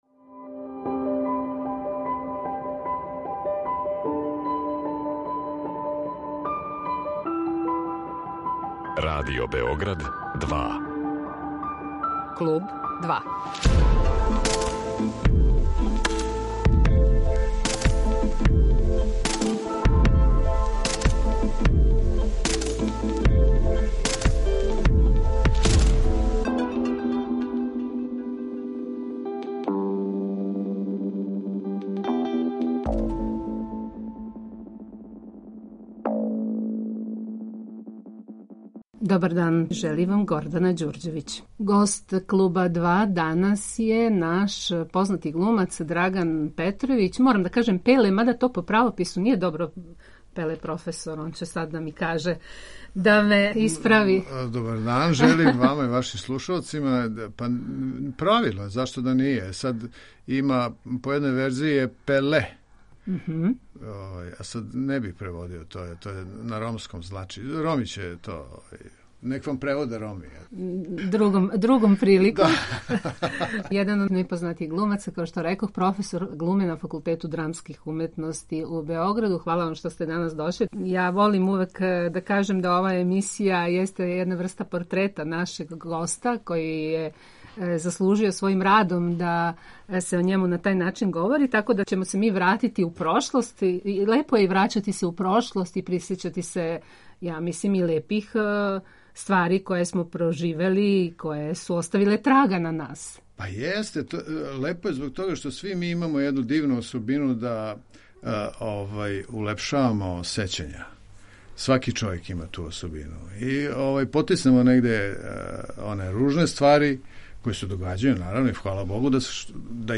Гост Клуба 2 је Драган Петровић Пеле, један од наших најпознатијих глумаца и професор глуме на Факултету драмских уметности у Београду.
Све је то био повод за разговор са Драганом Петровићем Пелетом.